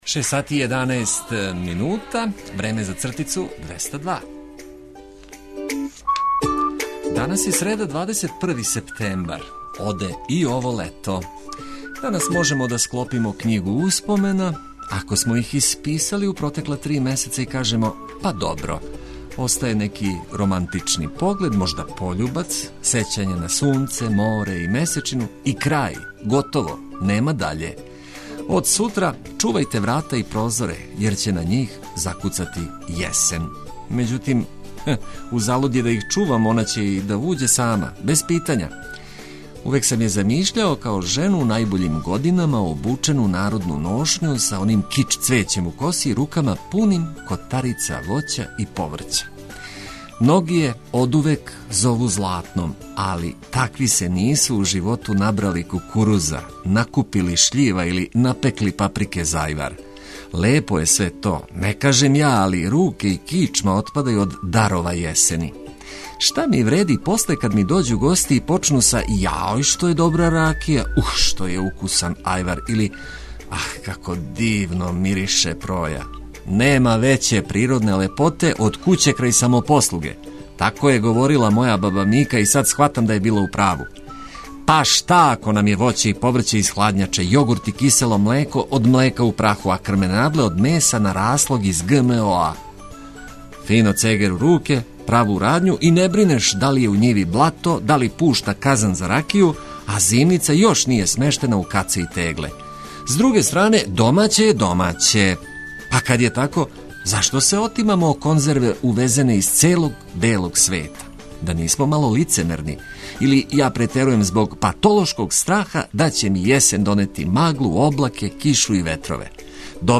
Буђење је пријатније уз ведру музику и приче од користи за све који нас ослушкују.